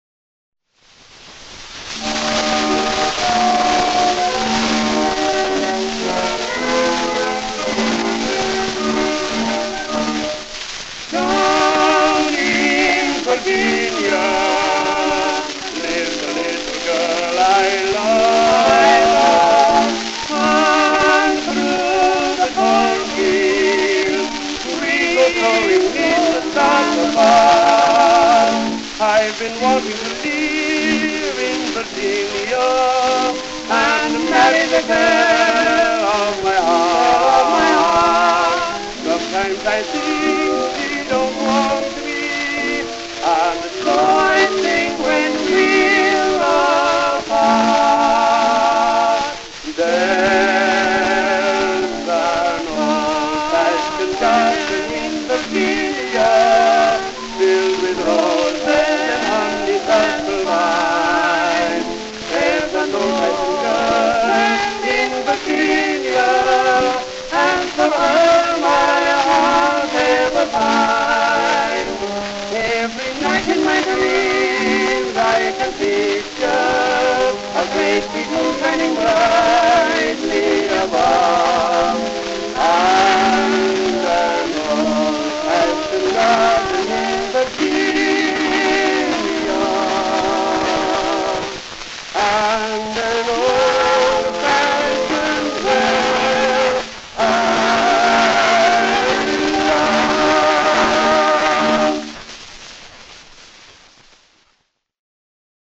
Tenor Duet